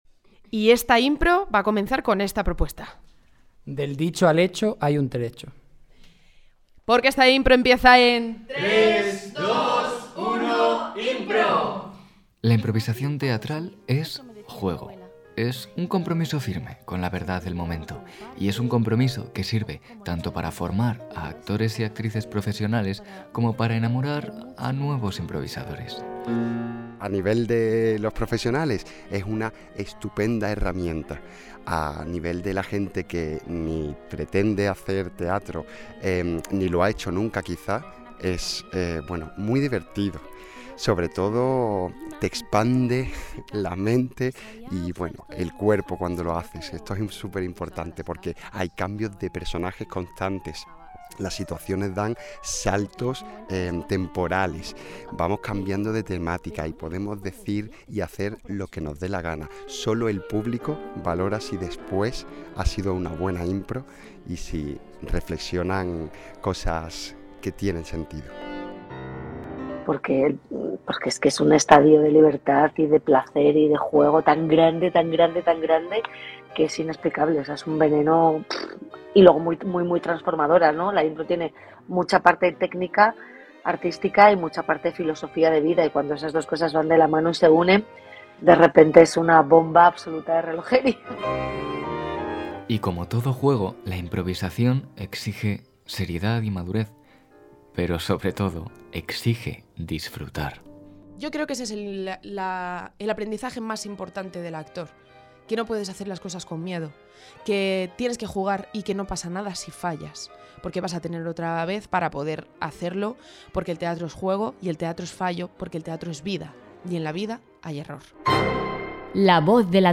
En él se podrán escuchar en cada episodio a actores y actrices profesionales, profesores de teatro, ejercicios de teatro de la Academia La Troyana, radioteatro…